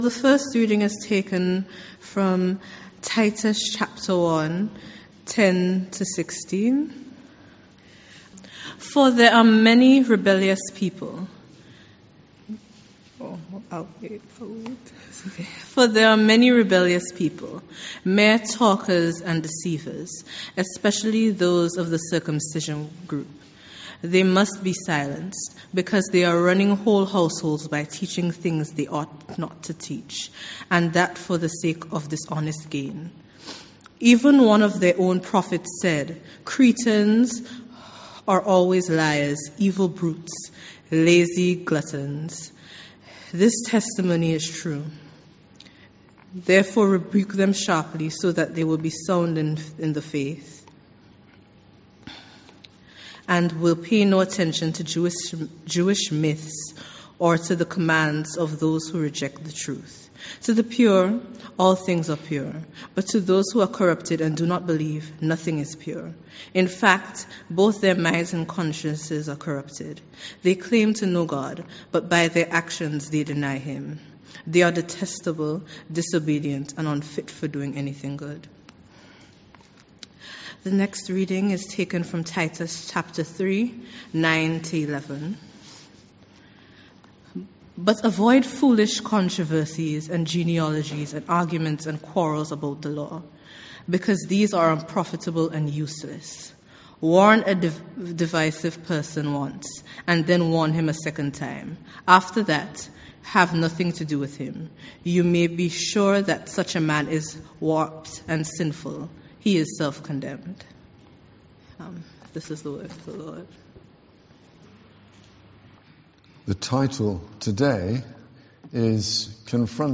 An audio recording of the service is available .